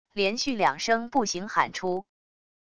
连续两声不行喊出wav音频